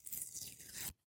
5. Звук отклеивания наклейки